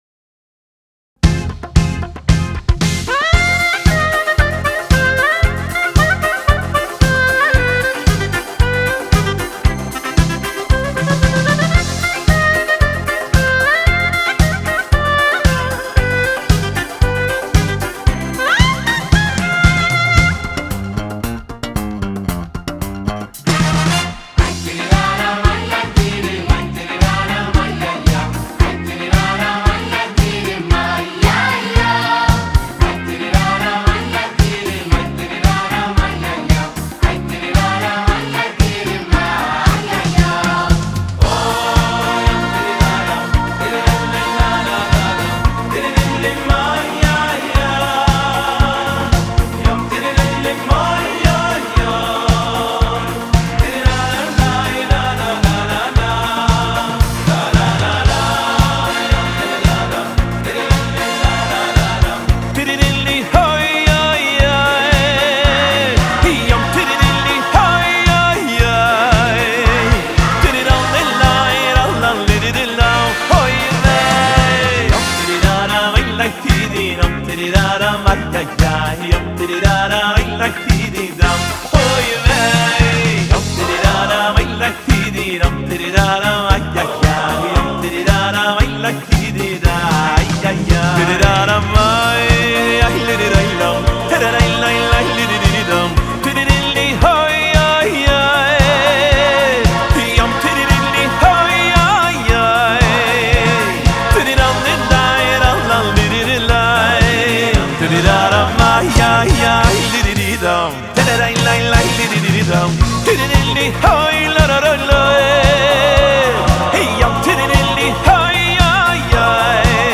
לחצו PLAY להאזנה לניגון שמחה מתוך האלבום